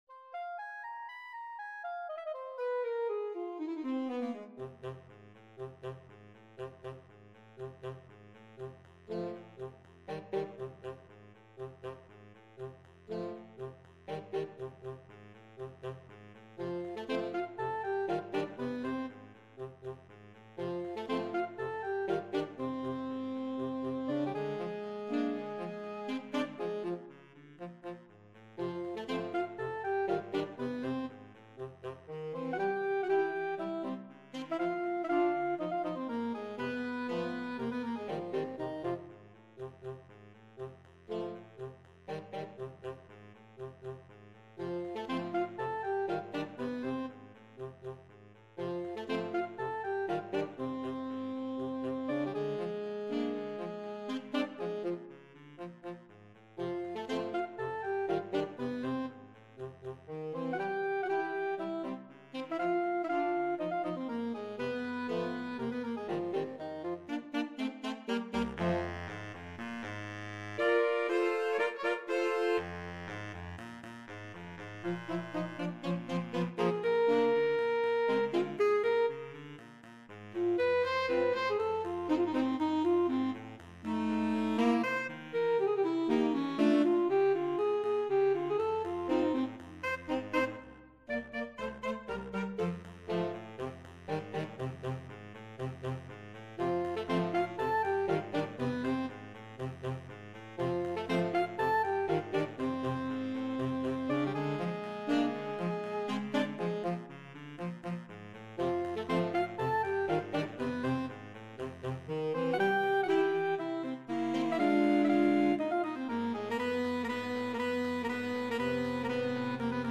für Saxophonchor
Instrumentalnoten für Saxophon PDF